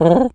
pigeon_call_calm_02.wav